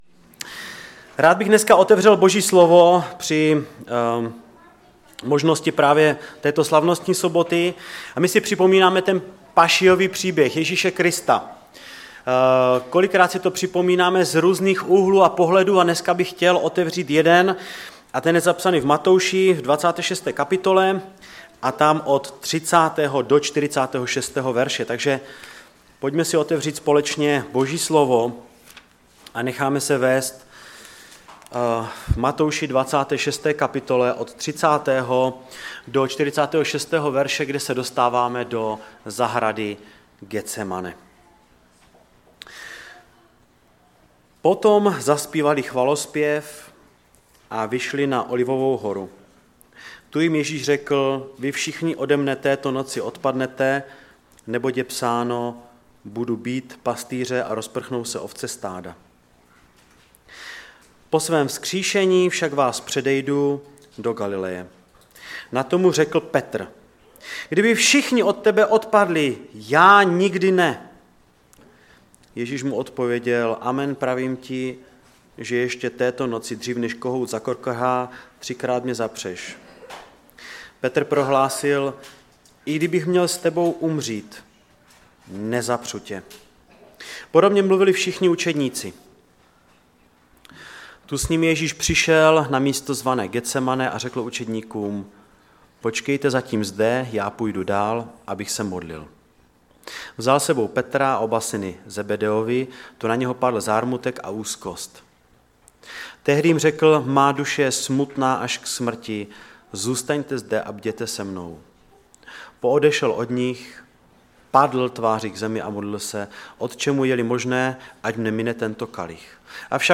Kázání
ve sboře Ostrava-Radvanice v rámci Památky Večeře Páně.